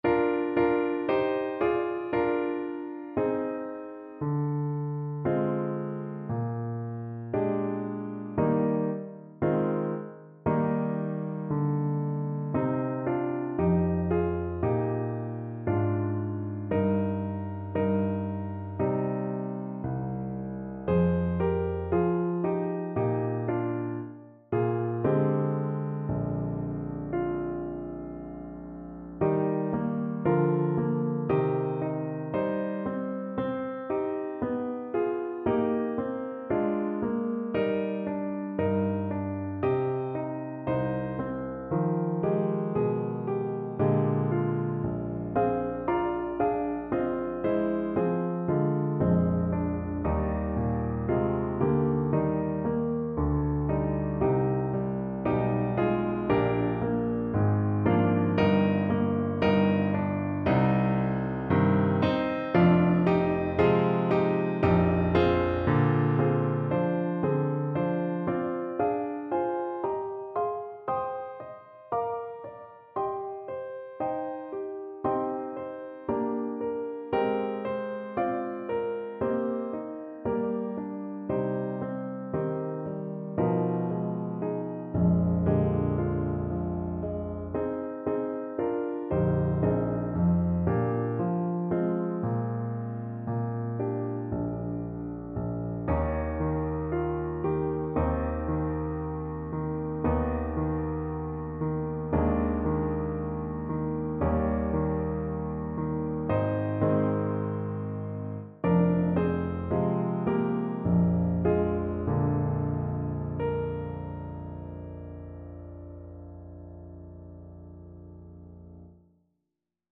Alto Saxophone
2/4 (View more 2/4 Music)
Traditional (View more Traditional Saxophone Music)